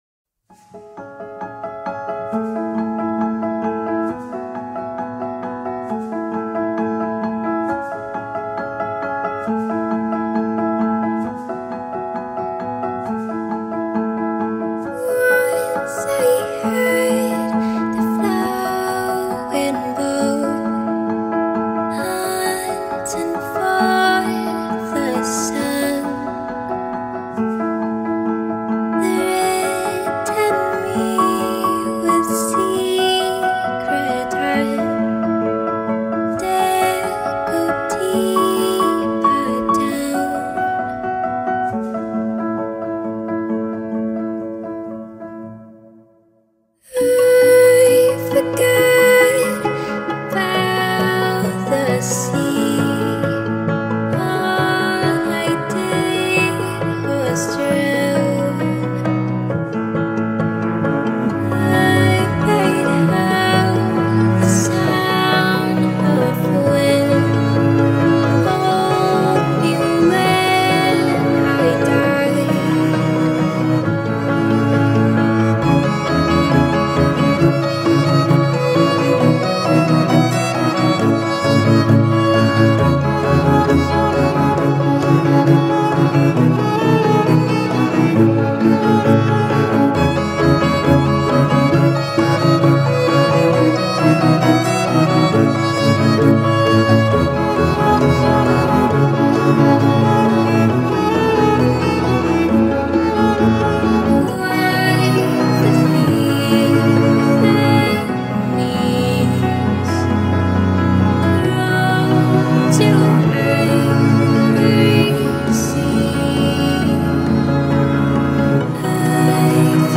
piano
the clear, striking vocals